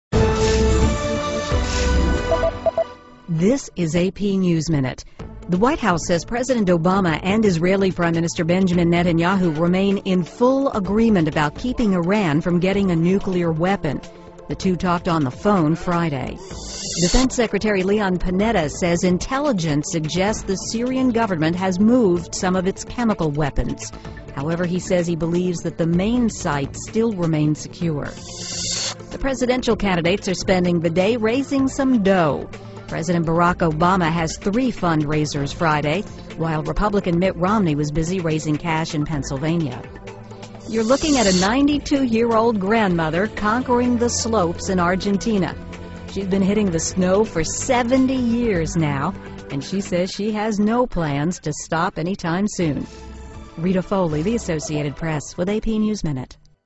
在线英语听力室美联社新闻一分钟 AP 2012-10-08的听力文件下载,美联社新闻一分钟2012,英语听力,英语新闻,英语MP3 由美联社编辑的一分钟国际电视新闻，报道每天发生的重大国际事件。电视新闻片长一分钟，一般包括五个小段，简明扼要，语言规范，便于大家快速了解世界大事。